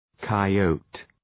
Shkrimi fonetik {kaı’əʋtı}